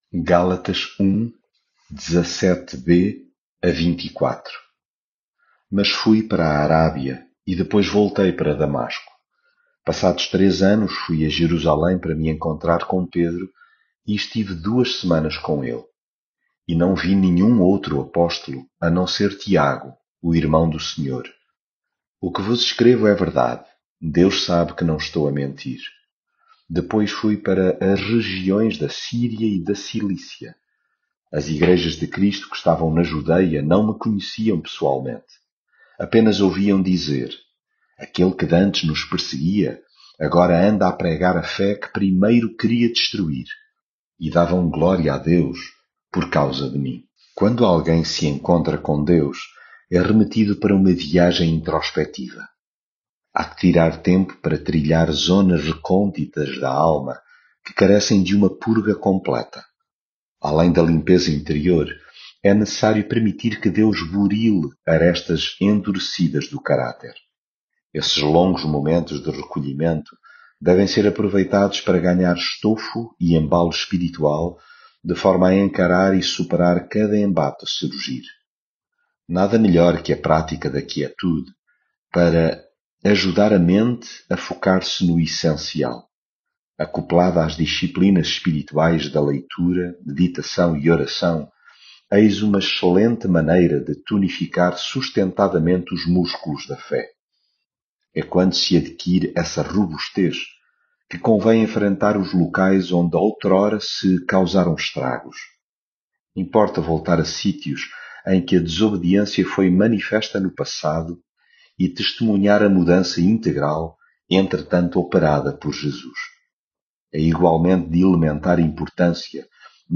devocional gálatas leitura bíblica Mas fui para a Arábia e depois voltei para Damasco.